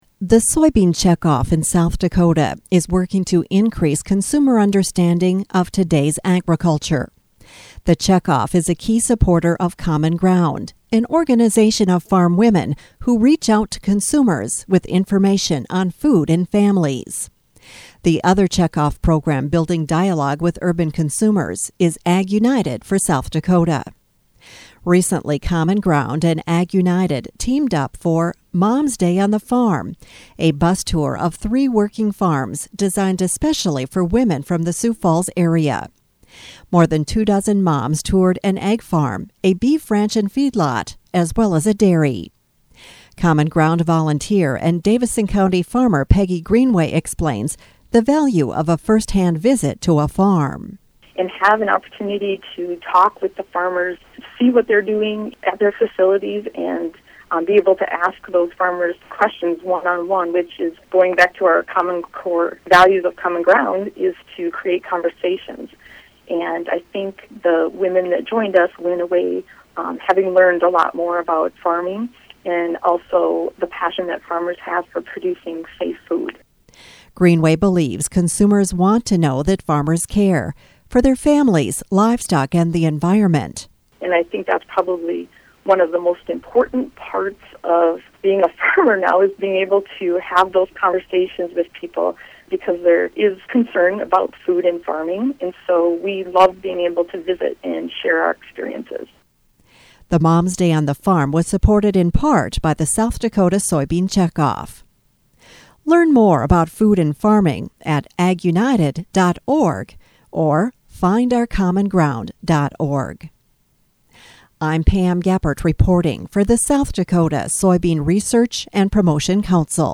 Mom's Day Radio Interview 2014